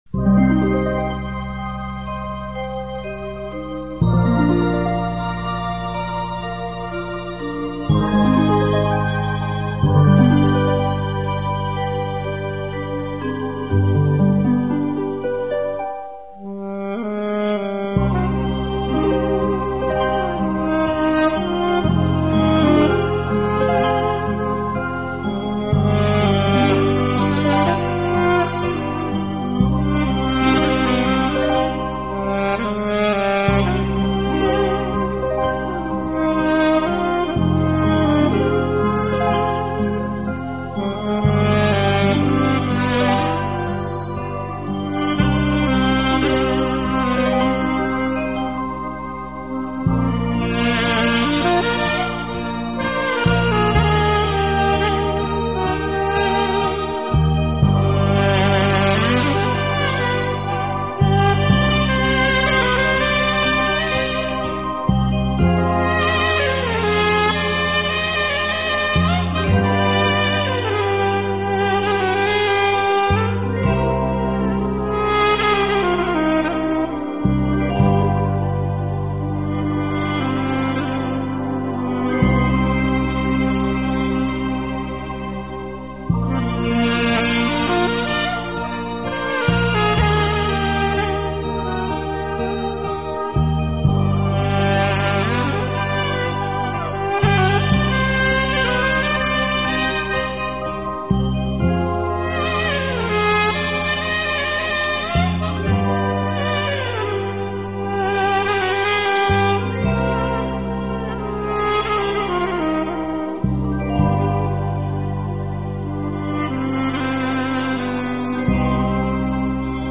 0387-查尔什舞曲-sax.mp3